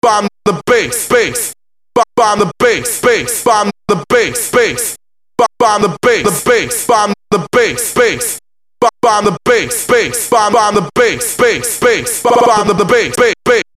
Главная » Файлы » Акапеллы » Скачать Фразы и Произношения